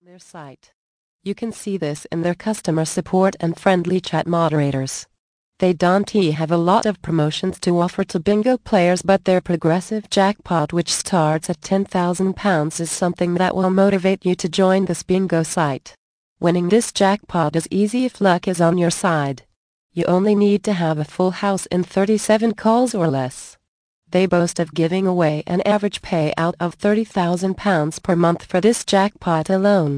Bingo Winning Secrets. Audio Book. Vol. 3 of 7. 54 min.